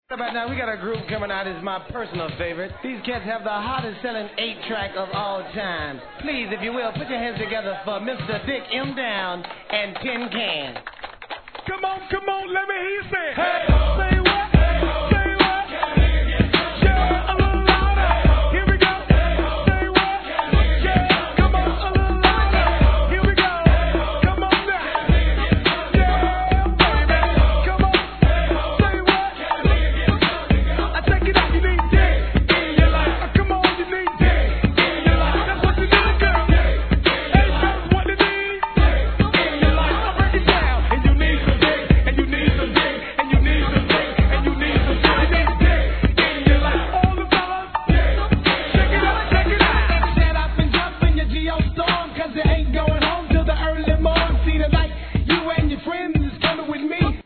G-RAP/WEST COAST/SOUTH
1995年、フロリダ産のおばかっぷり全快の煽りがたまらない怒インディー物!!